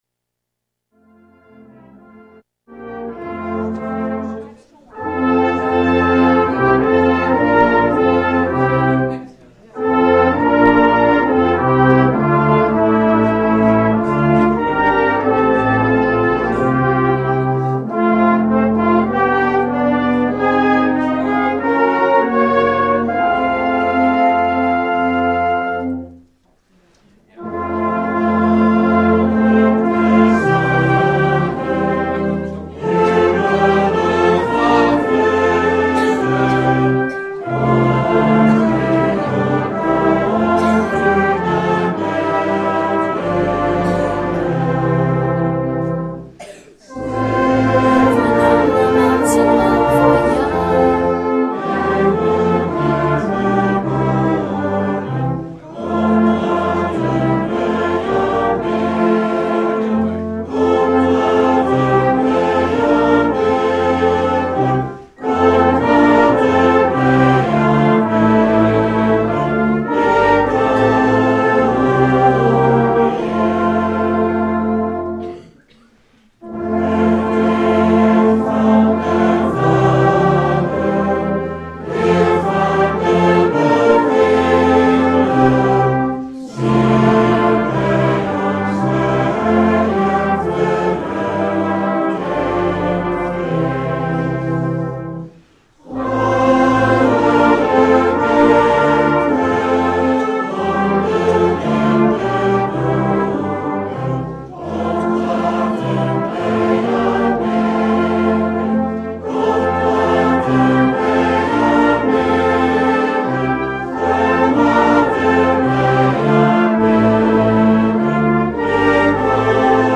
mmv het korps hallelujah uit Dr Compagnie (1e Kerstdag)